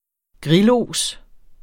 Udtale [ ˈgʁilˌoˀs ]